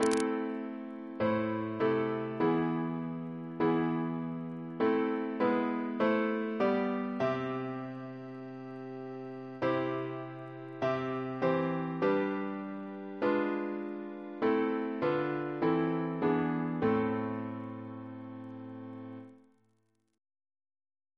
Double chant in A minor Composer: Sir John Goss (1800-1880), Composer to the Chapel Royal, Organist of St. Paul's Cathedral Reference psalters: ACP: 77